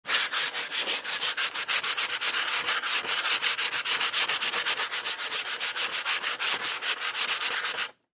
in_sandpaper_01_hpx
Wood being sanded by hand. Tools, Hand Wood, Sanding Carpentry, Build